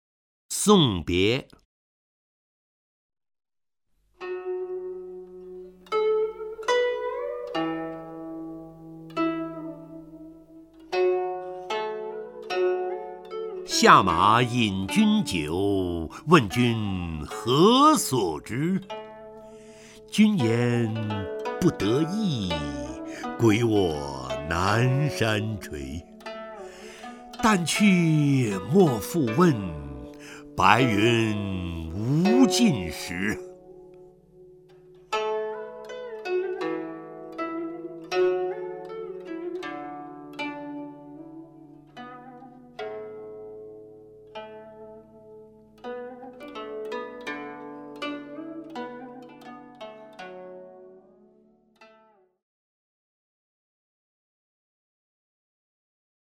陈醇朗诵：《送别》(（唐）王维) （唐）王维 名家朗诵欣赏陈醇 语文PLUS